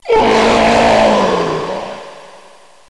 zombi_death_2.mp3